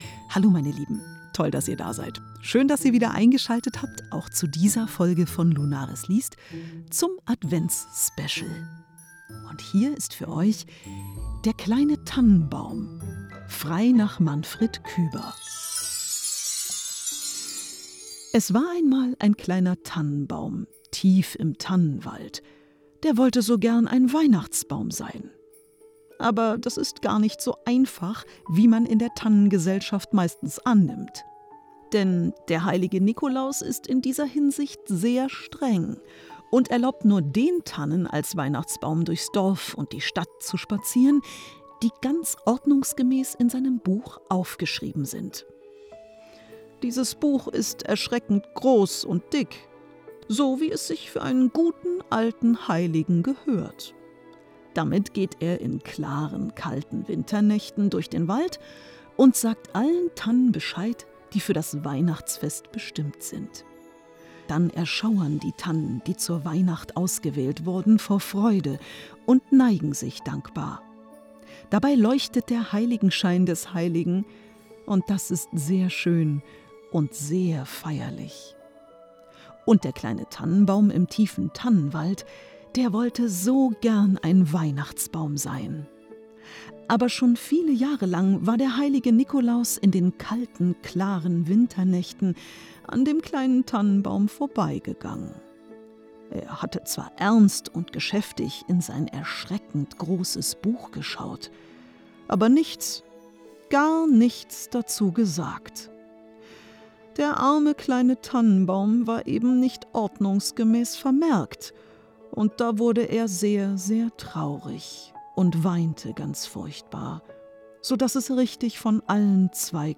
Kyber in moderner Sprache, sanft vorgelesen – ideal zum
Adventsgeschichte, Vorweihnachtszeit, Märchen vorgelesen,